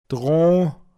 Wortlisten - Pinzgauer Mundart Lexikon
dran drå(n)